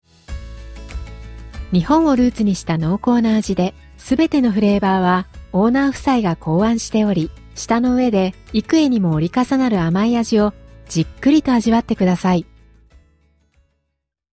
日本語音声ガイド